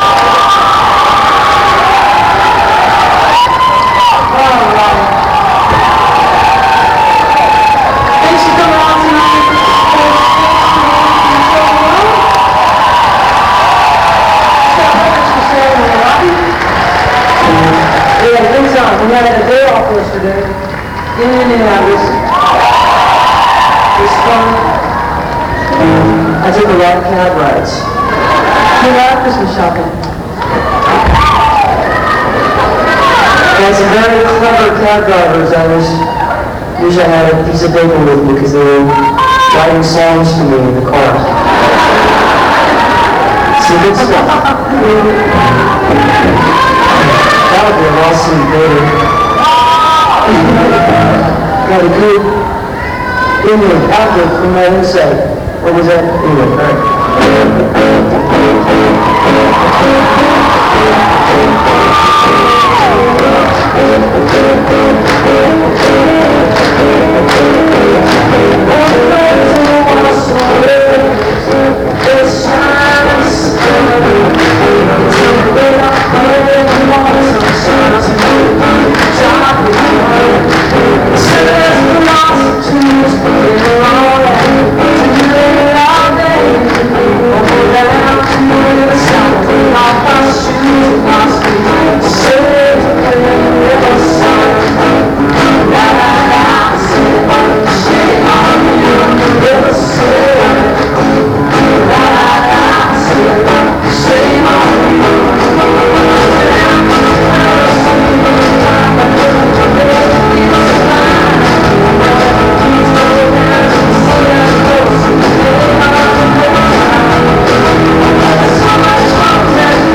(this recording has some distortion)